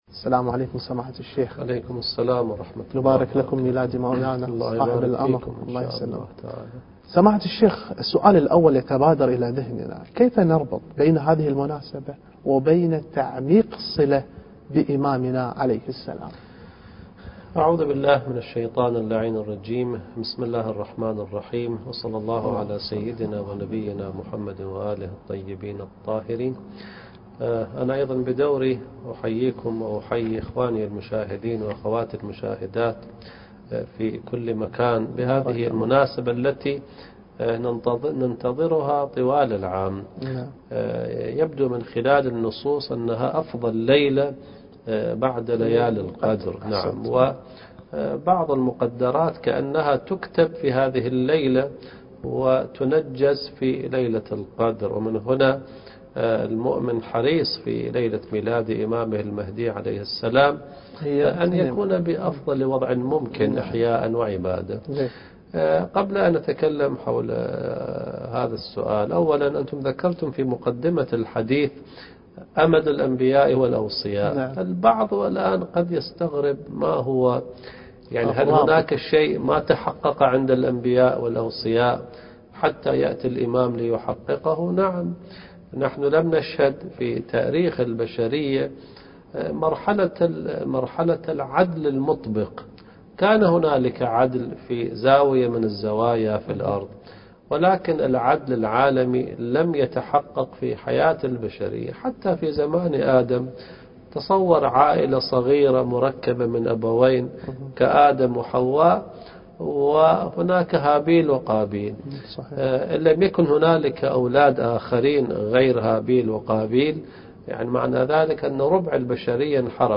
برنامج حواري بمناسبة ولادة الامام المهدي عليه السلام قناة المعارف الفضائية